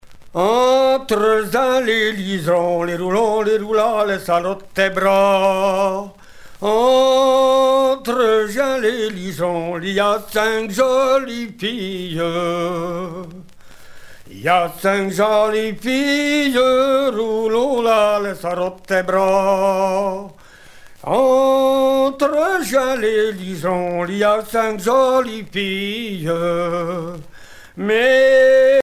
Genre laisse
Chanteurs et musiciens de Villages en Morvan, vol. 3
Pièce musicale éditée